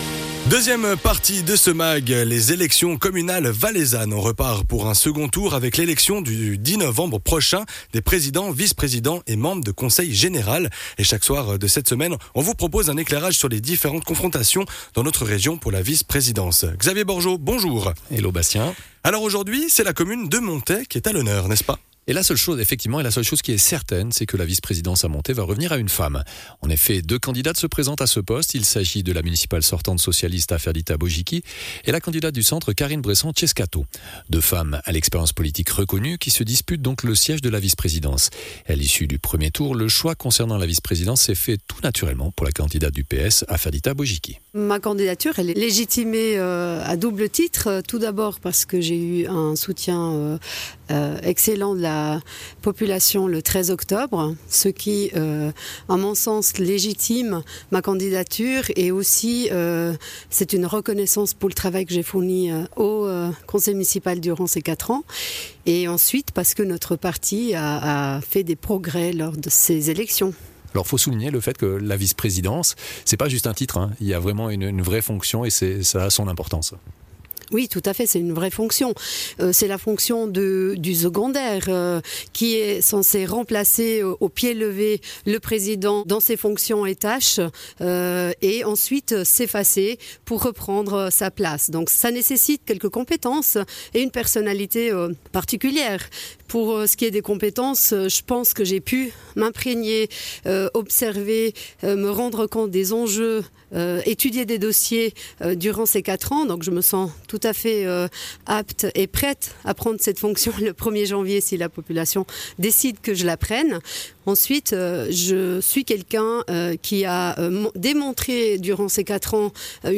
Duel à la vice-présidence à Monthey: les entretiens des 2 candidates